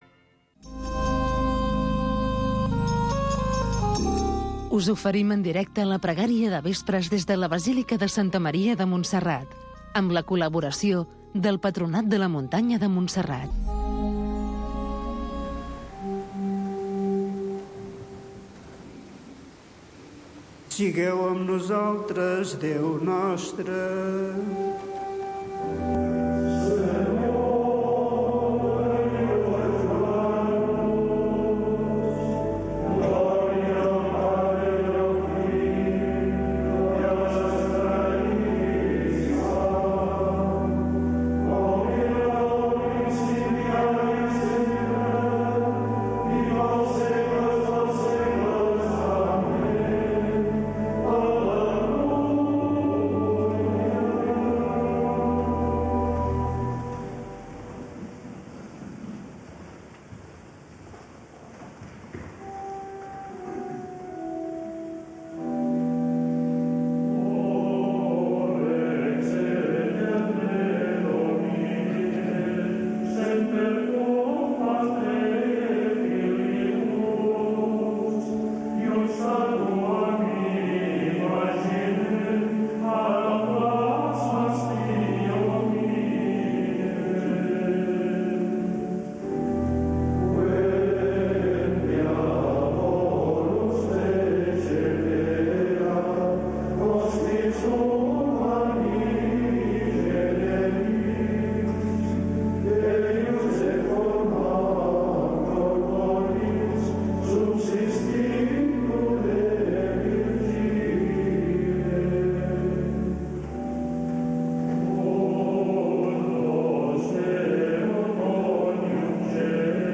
Pregària del vespre per donar gràcies al Senyor. Amb els monjos de Montserrat, cada dia a les 18.45 h